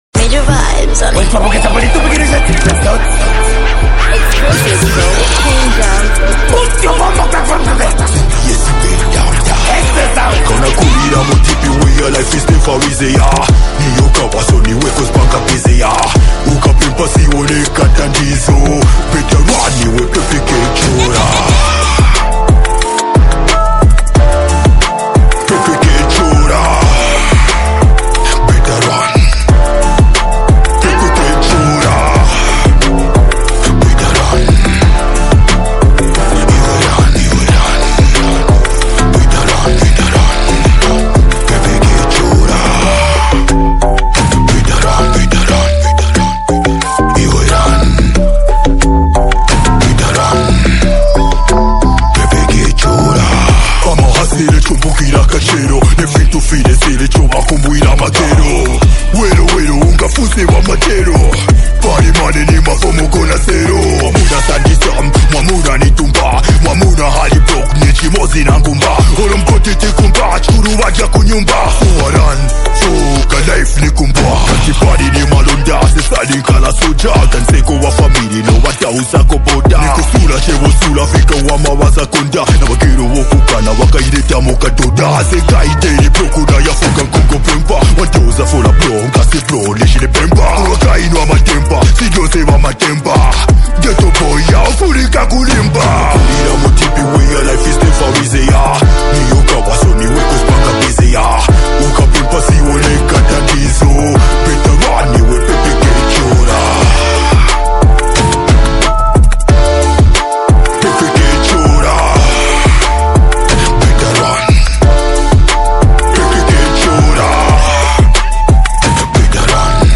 is a reflective and emotionally grounded song
with a soulful hook that adds emotional depth